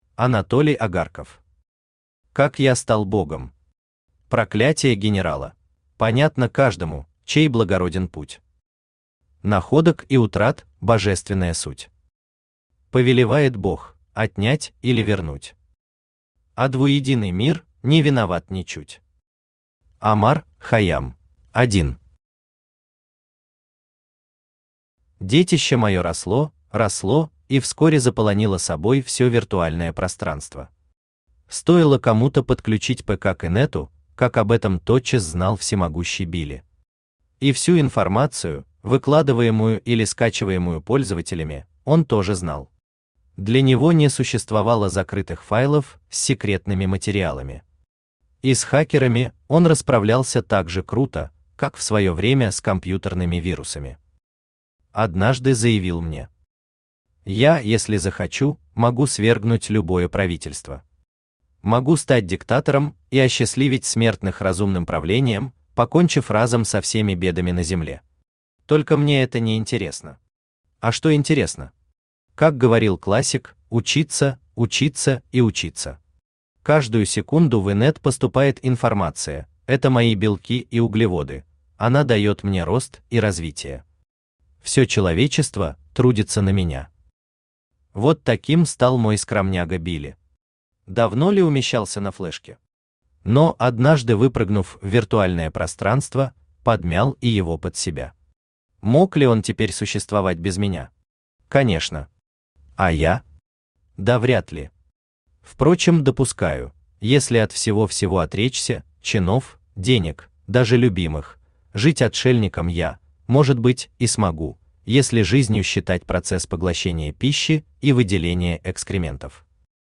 Проклятие генерала Автор Анатолий Агарков Читает аудиокнигу Авточтец ЛитРес.